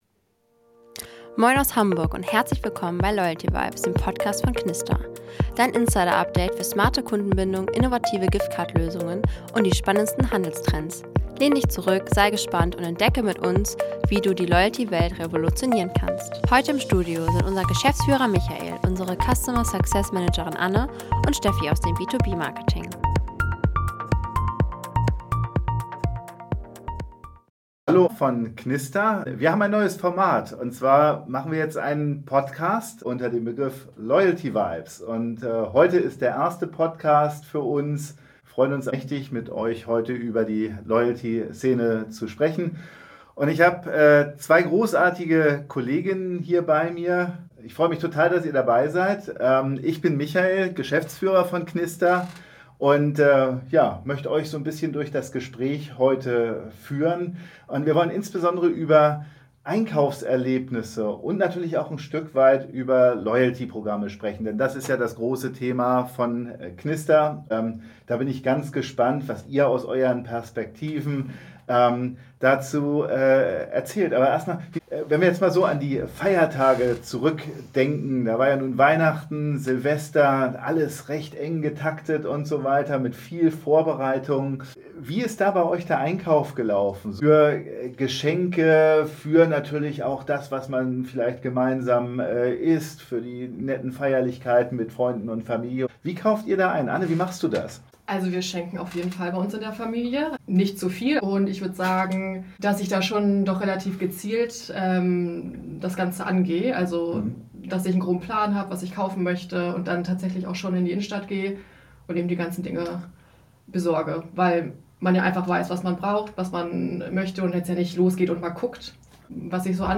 mit zwei Kolleginnen über ihr persönliches Einkaufsverhalten und beleuchtet die spannende Welt zwischen Online- und Offline-Kauf. Hör rein, um zu erfahren, wie Loyalty- und Giftcard-Programme das Konsumentenverhalten noch stärker prägen!